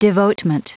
Prononciation du mot devotement en anglais (fichier audio)
Prononciation du mot : devotement